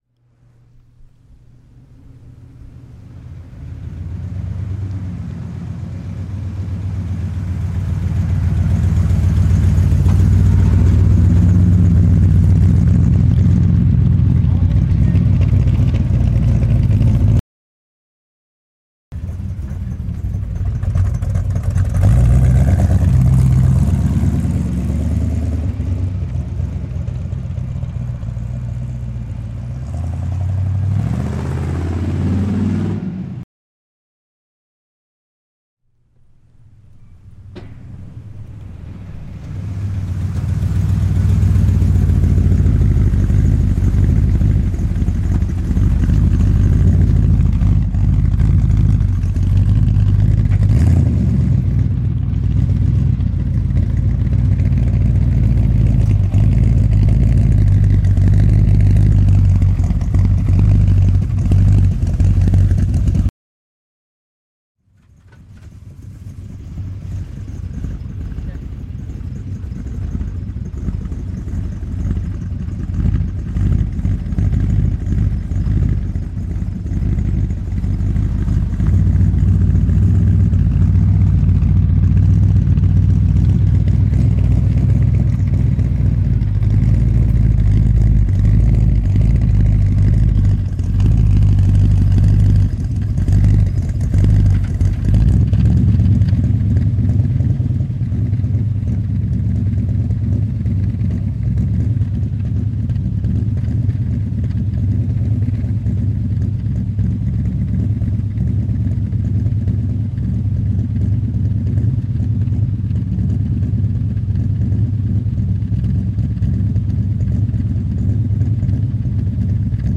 random " auto performance car ext pull up slowly and drive forward slowly and pull away
描述：汽车性能汽车ext慢慢拉起来，慢慢向前行驶并拉开
Tag: 性能 驱动 向上走 汽车 速度慢